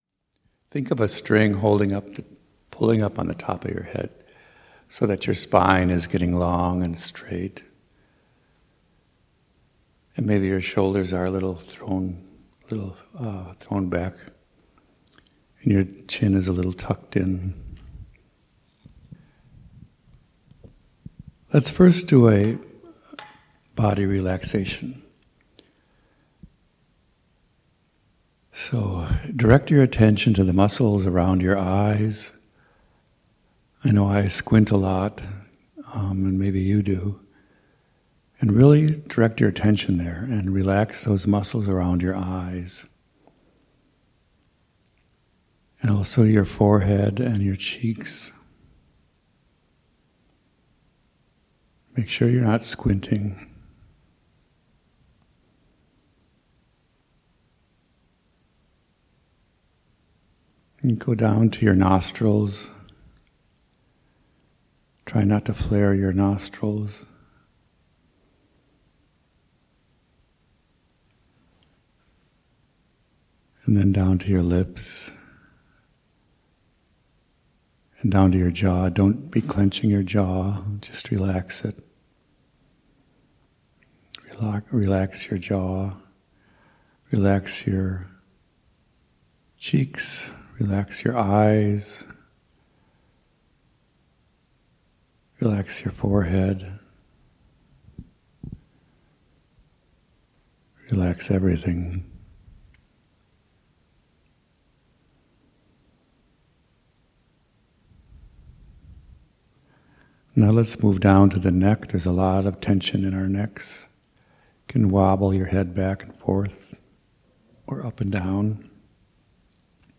Dharma talks, guided meditations, and more, recorded at the Blue Lotus Buddhist Temple.